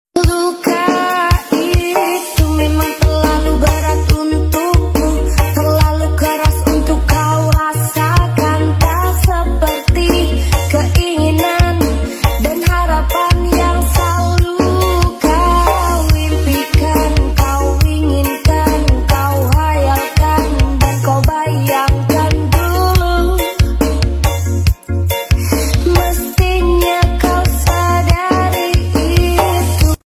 CapCut Kucing Tiktok lucu gemess